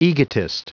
Prononciation du mot egotist en anglais (fichier audio)
Prononciation du mot : egotist